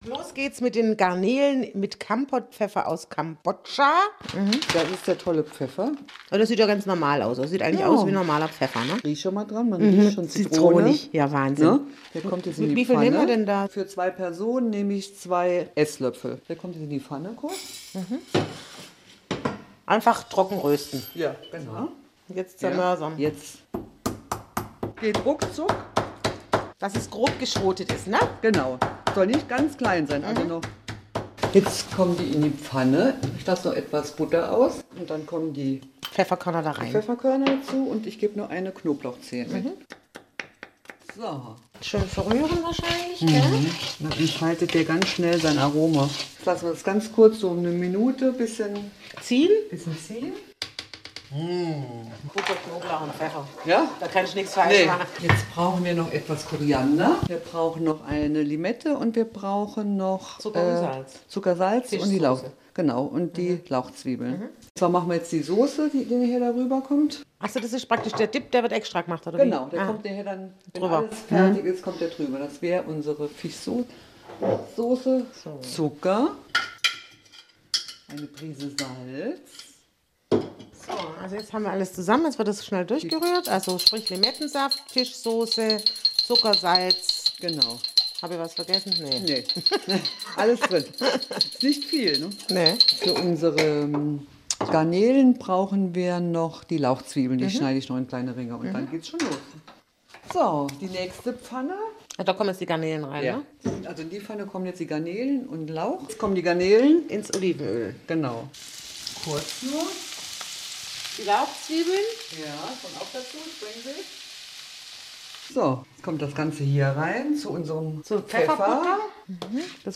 Zu hören war es am Samstag, 19.05.2018 11:00 Uhr auf SR 3 Saarlandwelle in der Sendung Bunte Funkminuten, ein Service-Magazin am Vormittag im Saarland.